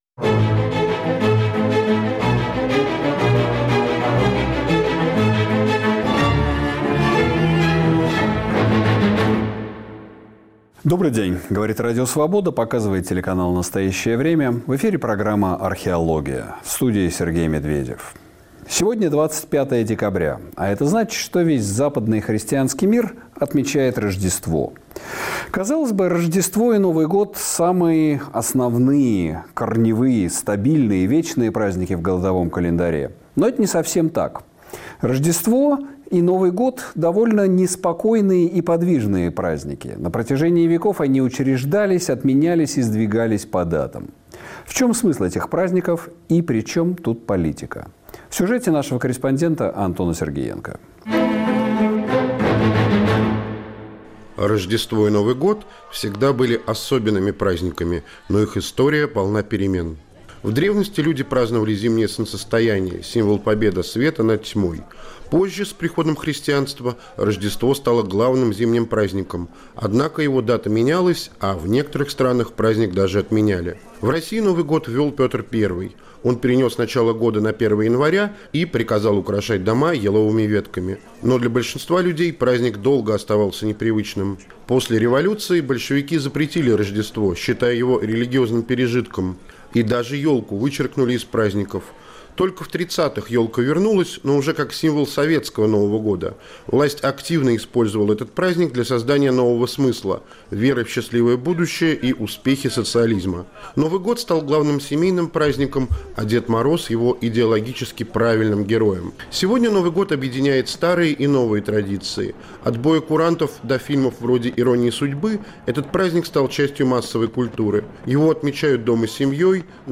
богослов Андрей Кураев